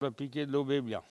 Elle provient de Saint-Hilaire-de-Riez.
Locution ( parler, expression, langue,... )